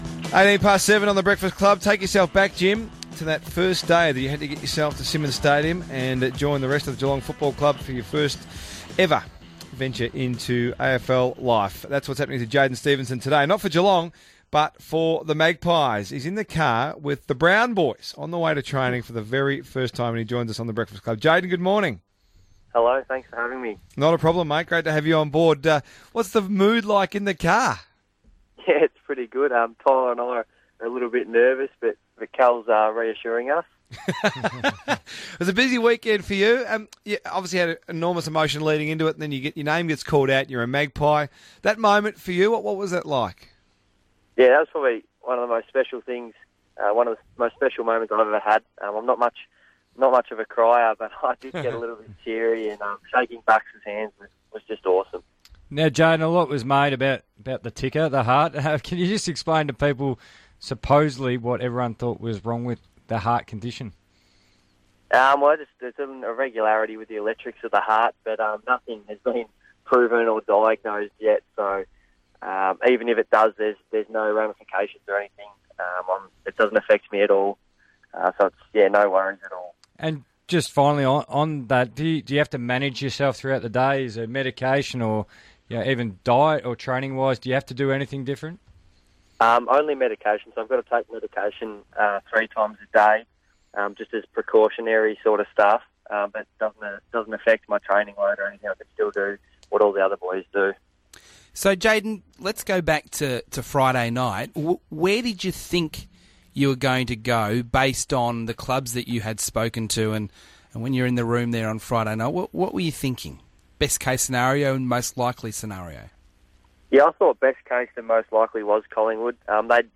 Listen in as new draftee Jaidyn Stephenson talks to RSN on his first morning as a Collingwood player.